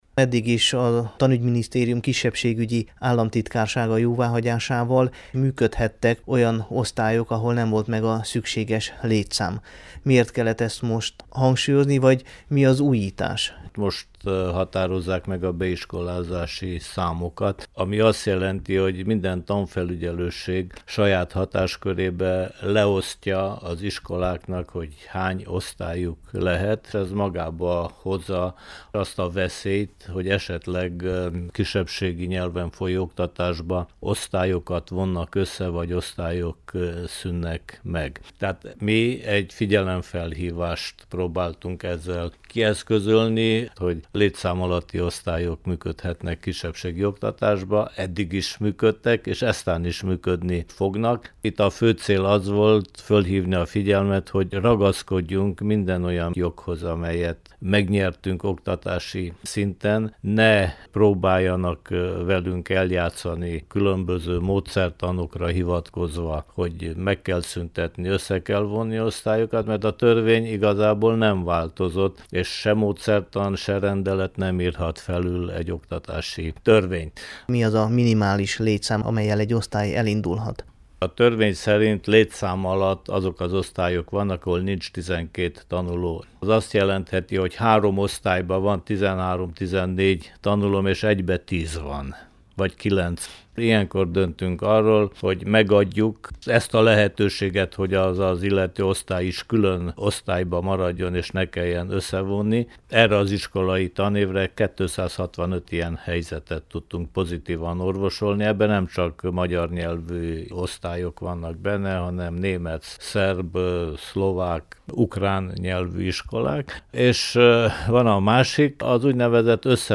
„Ragaszkodjunk az oktatásban elért jogainkhoz” – interjú Király András államtitkárral [AUDIÓ]